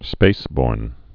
(spāsbôrn)